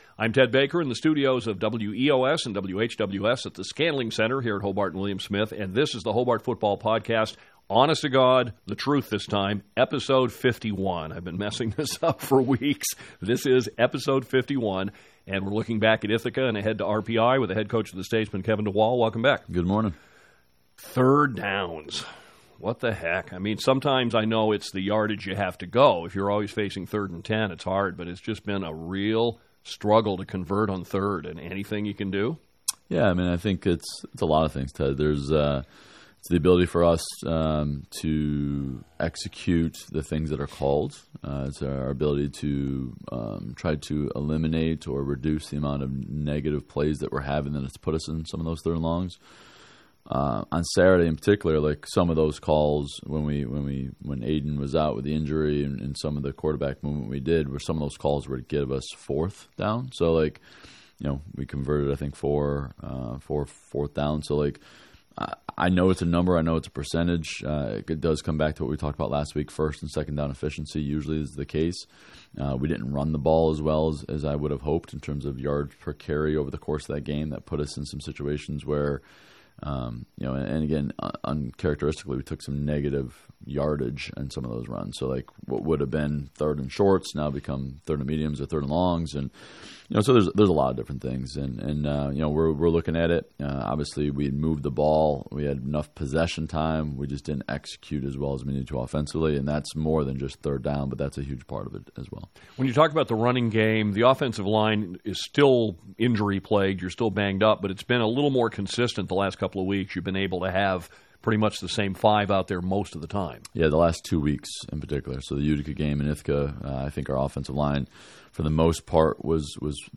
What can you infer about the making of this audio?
The Hobart Football Podcast is recorded weekly during the season.